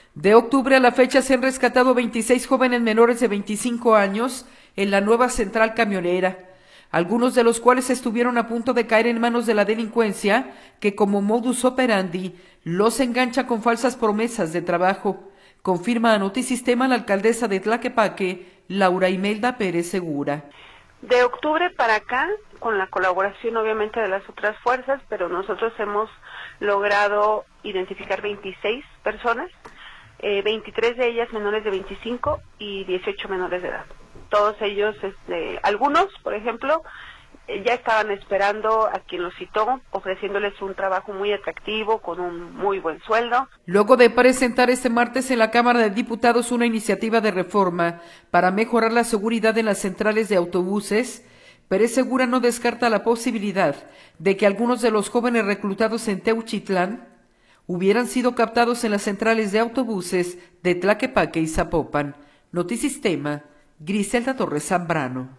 De octubre a la fecha se han rescatado a 26 jóvenes menores de 25 años en la Nueva Central Camionera, algunos de los cuales estuvieron a punto de caer en manos de la delincuencia que como modus operandi, los engancha con falsas promesas de trabajo, confirma a Notisistema la alcaldesa de Tlaquepaque, Laura Imelda Pérez Segura.